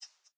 minecraft / sounds / mob / rabbit / idle3.ogg